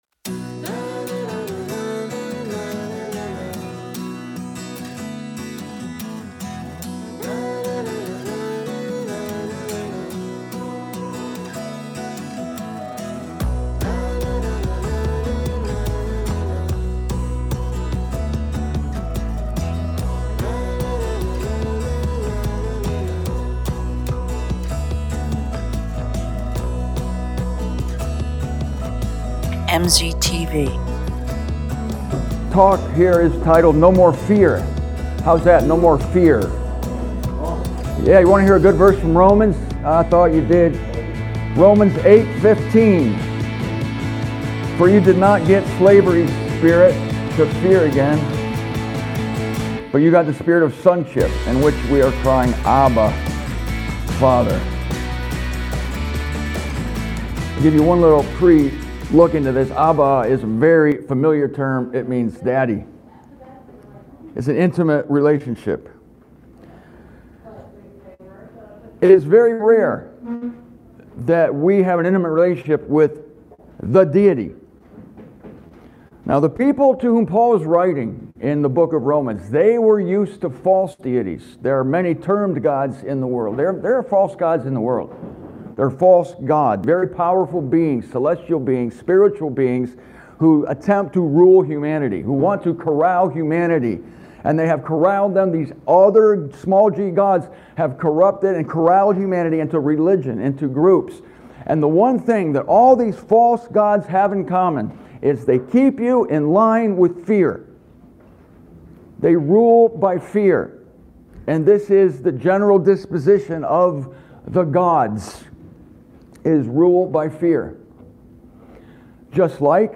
Last fall I spoke in Nehawka, Nebraska about how a God (THE God) of Whom we need not be afraid.
But the true God is One we can call "Abba," that is, "Father." The audio from Nehawka conks out at about the 20-minute mark, so I finish this talk on my patio.